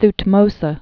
(tht-mōsə) Died 1452 BC.